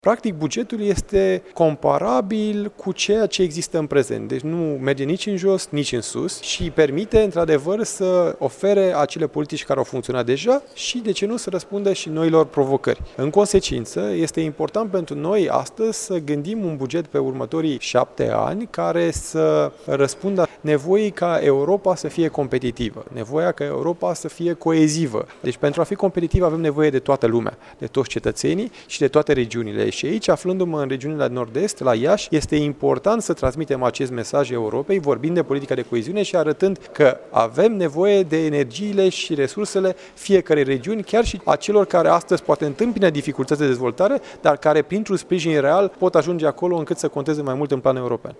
El participă la Universitatea Alexandru Ioan Cuza la Conferinţa Internaţională EUROINT, unde sunt prezenţi invitaţi din România, Republica Moldova şi Ucraina.